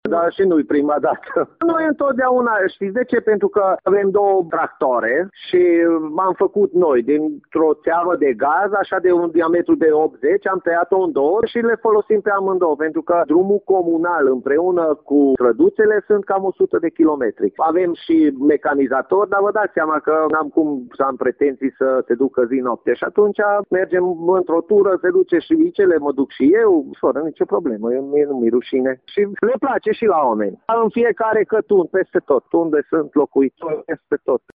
Primarul Comunei Gornești, Kolcsar Gyula: